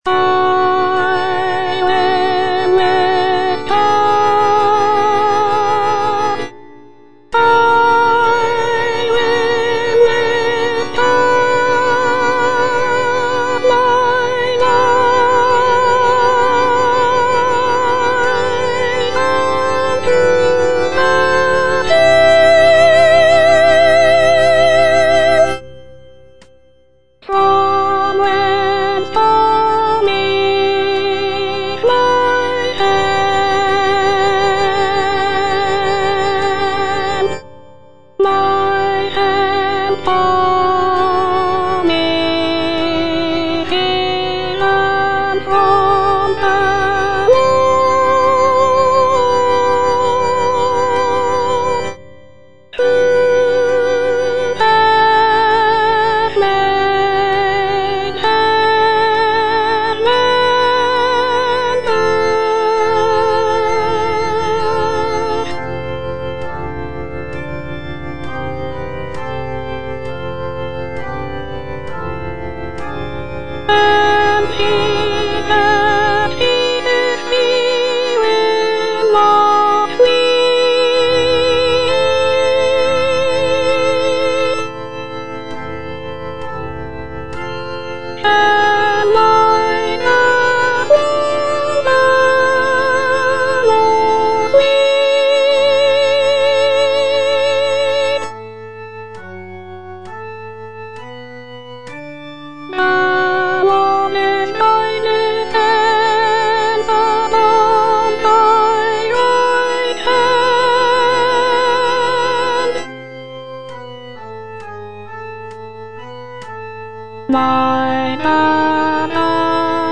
Soprano II (Voice with metronome)
choral work